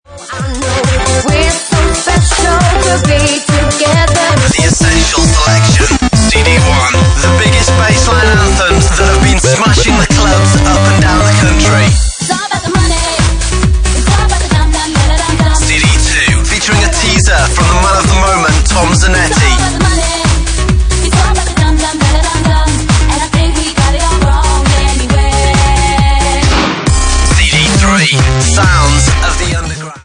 Genre:Bassline House
Bassline House at 142 bpm